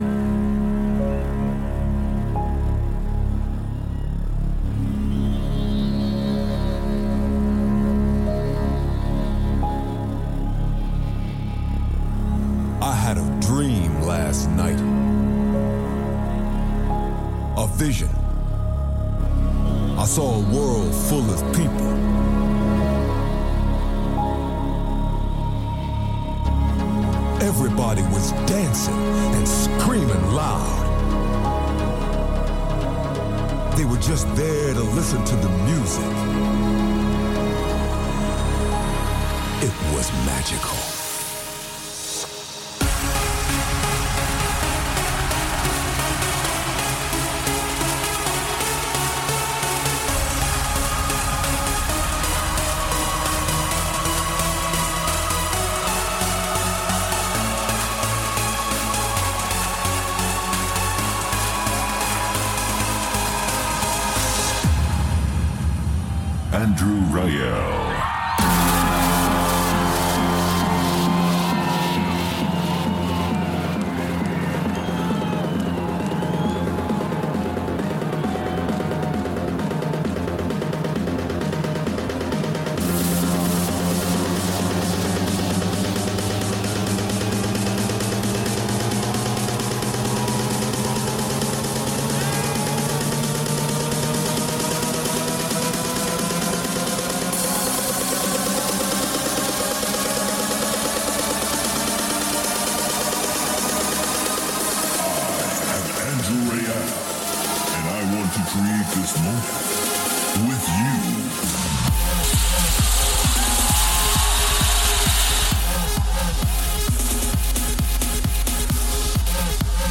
Also find other EDM Livesets, DJ
Liveset/DJ mix